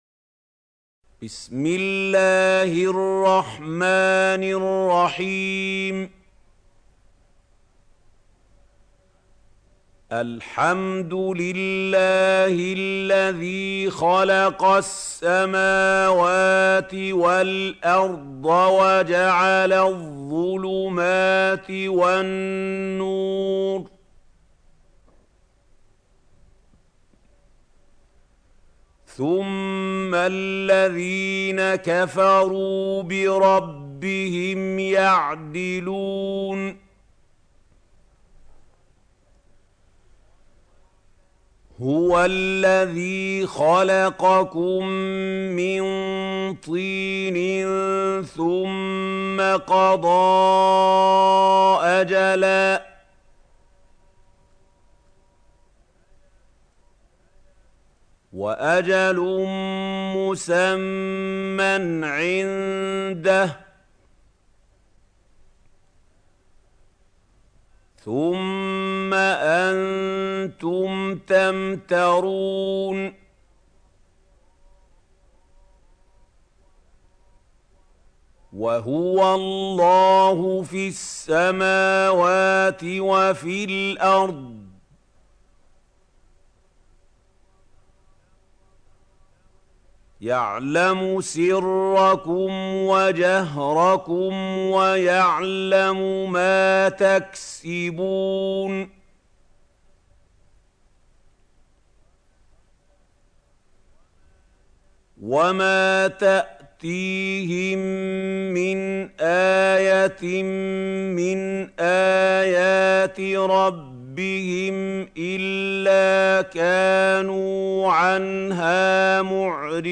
سورة الأنعام | القارئ محمود خليل الحصري - المصحف المعلم